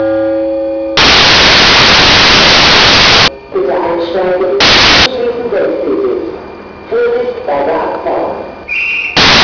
Station Announcement
STATION_ANOUCEMENT.wav